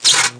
ZZZAP.mp3